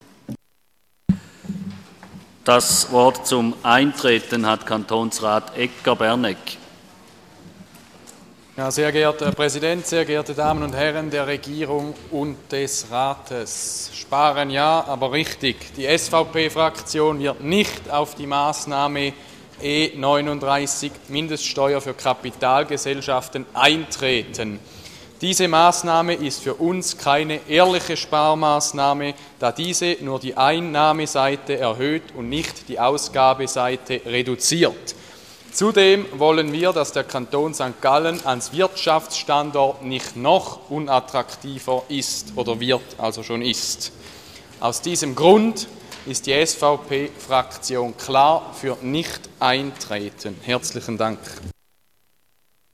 Session des Kantonsrates vom 24. und 25. Februar 2014